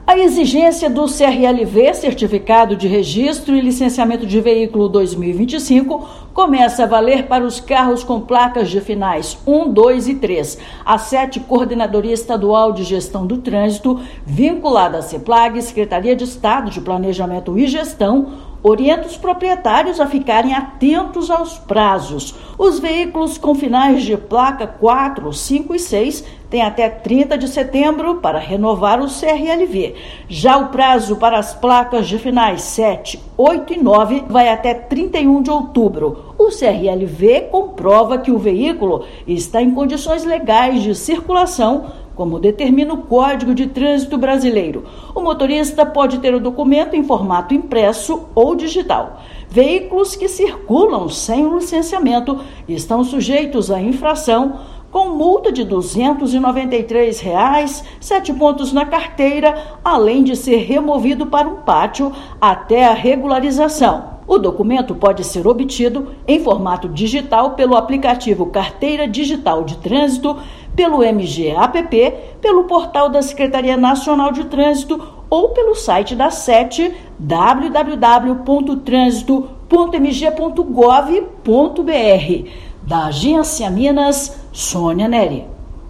Documento comprova que o carro está em condições legais de circulação. Ouça matéria de rádio.